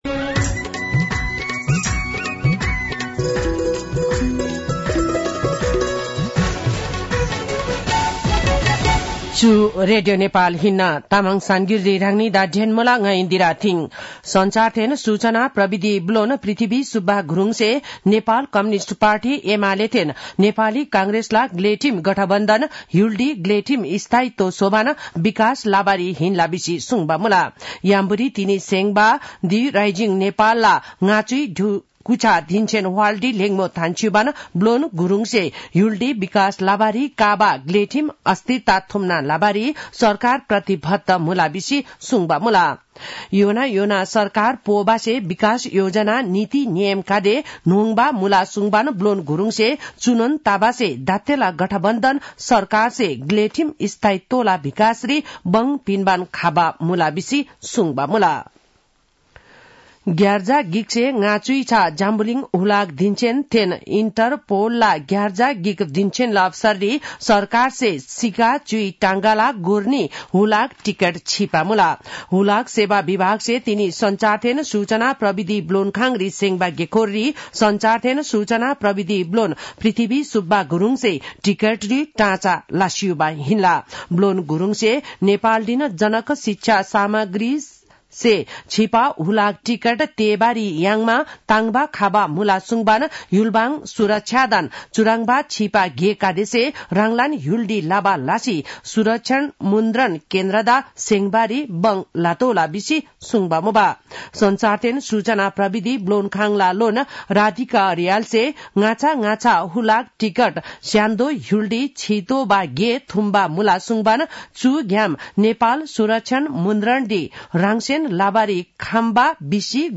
तामाङ भाषाको समाचार : २ पुष , २०८१
Tamang-News-9-1.mp3